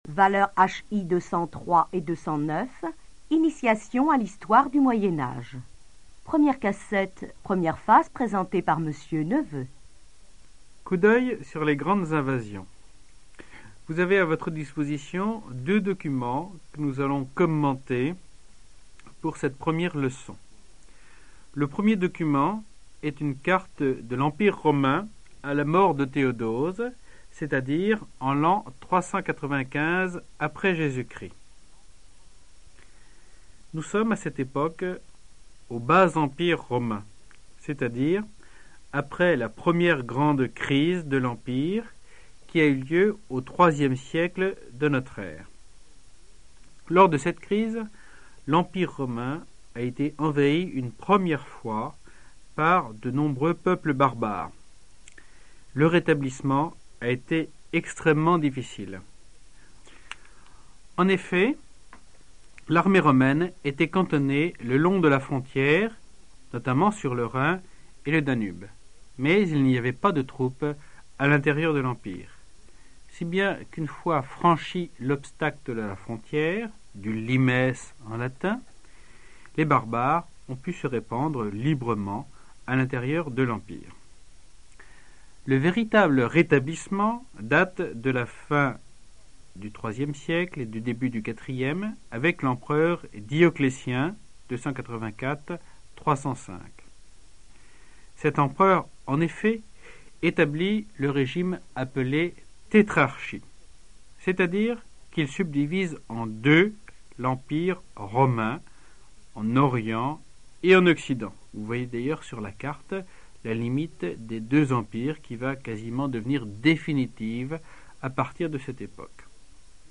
Archives cours audio 1987-1988